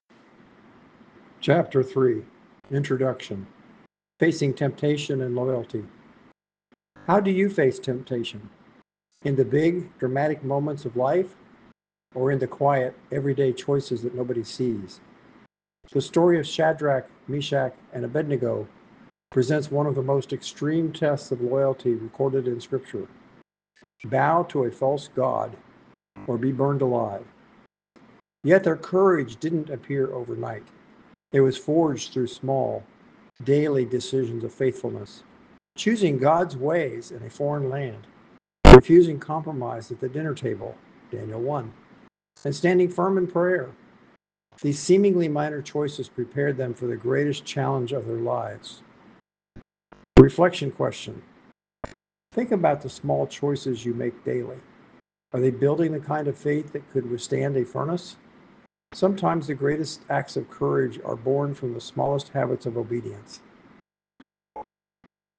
* Pronúncia clara
* Ritmo natural do idioma